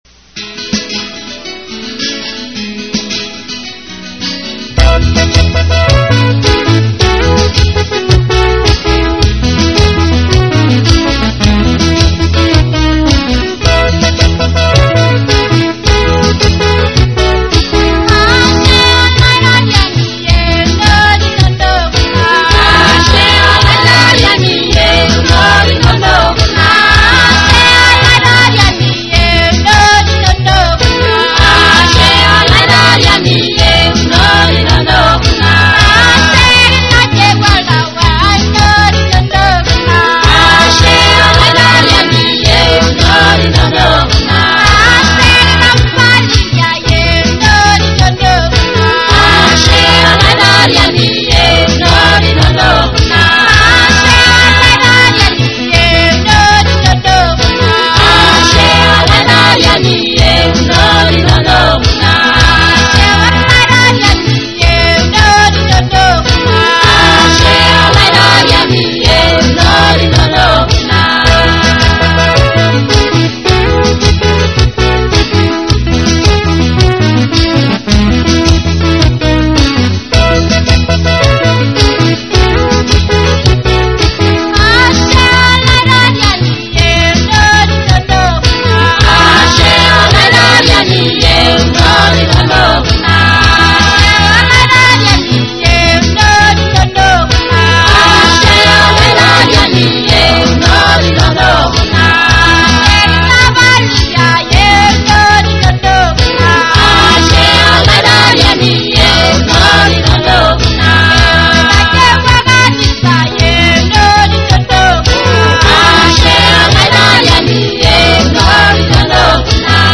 And some Christian songs in Maasai/Samburu.